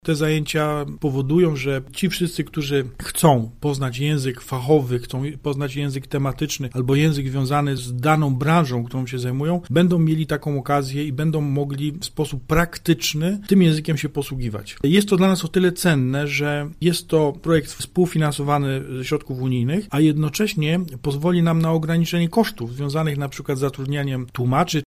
– W takim mieście jak nasze znajomość tylko rodzimego języka to dziś zbyt mało – mówi Tomasz Ciszewicz, burmistrz Słubic: